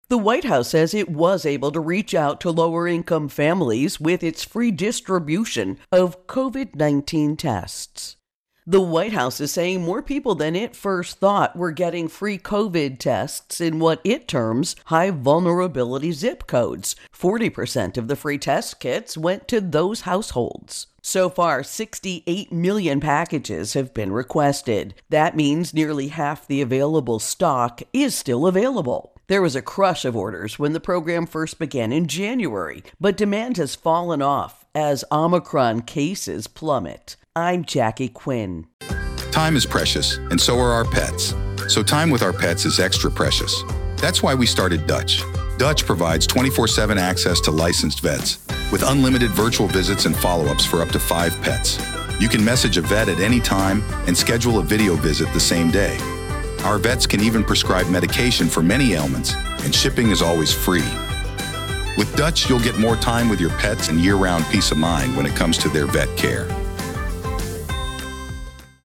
Virus Outbreak Free Tests Intro and Voicer